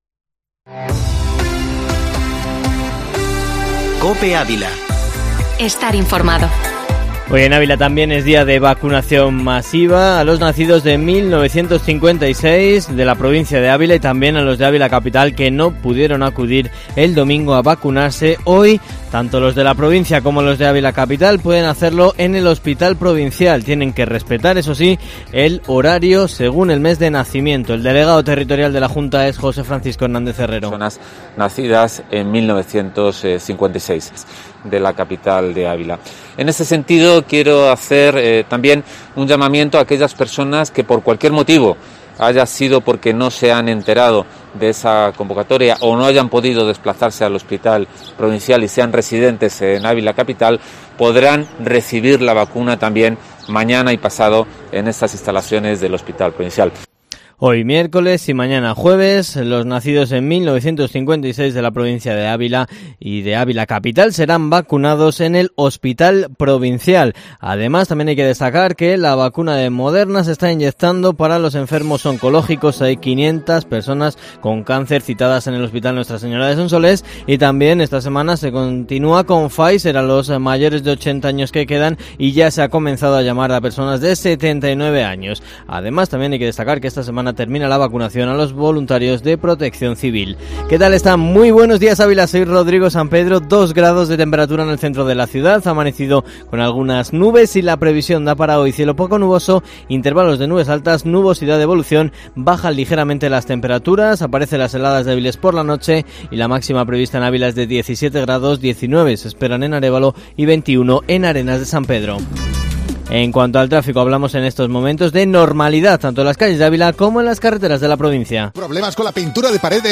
Informativo matinal Herrera en COPE Ávila 07/04/2021